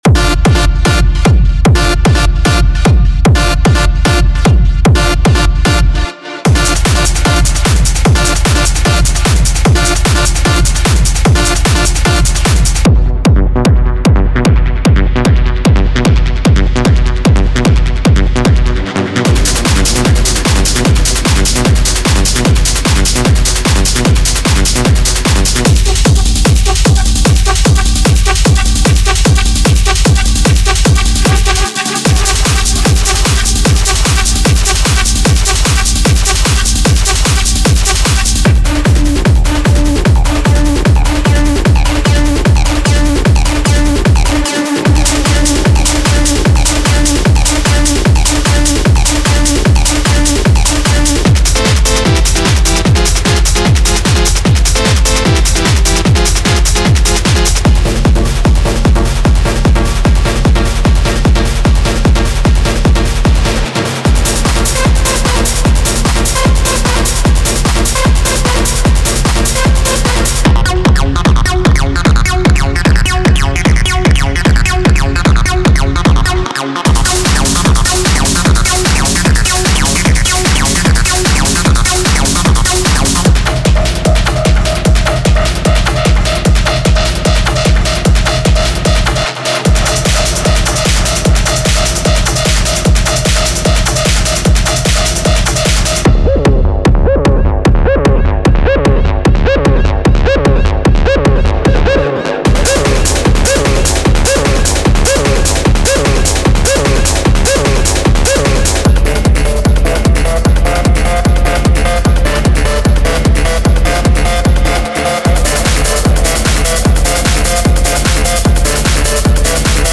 Genre:Techno
デモサウンドはコチラ↓
150 bpm